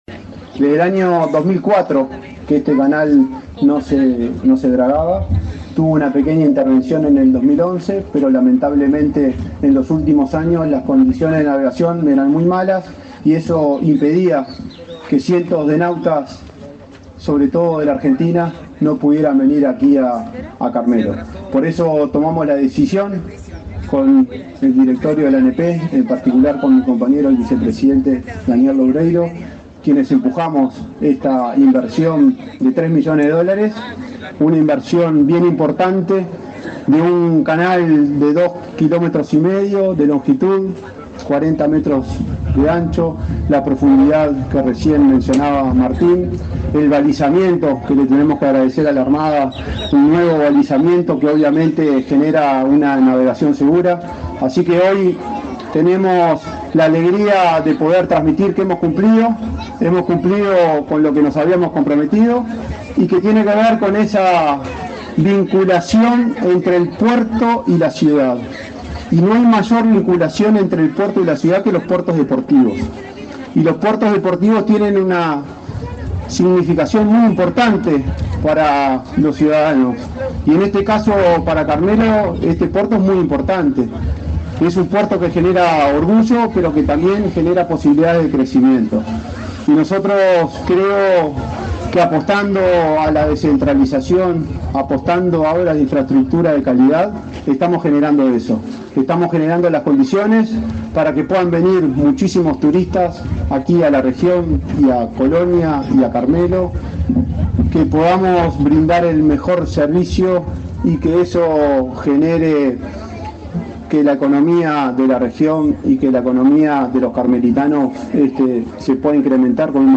Palabras de autoridades en acto de la ANP en Colonia
Palabras de autoridades en acto de la ANP en Colonia 26/08/2024 Compartir Facebook X Copiar enlace WhatsApp LinkedIn El presidente de la Administración Nacional de Puertos (ANP), Juan Curbelo, y el ministro de Transporte, José Luis Falero, participaron, este lunes 26, en la inauguración de las obras de dragado del canal sur de la isla Sola en el puerto de Carmelo, departamento de Colonia.